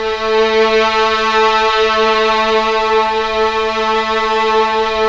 Index of /90_sSampleCDs/Club-50 - Foundations Roland/STR_xVioln Ens 1/STR_xVln Ens 1dM